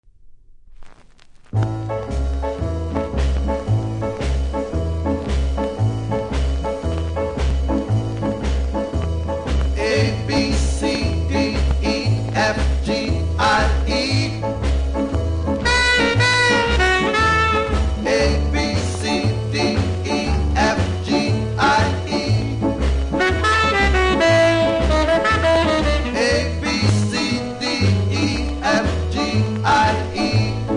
CONDITION：VG- ( NC )
深めのキズがありノイズ感じますので試聴で確認下さい。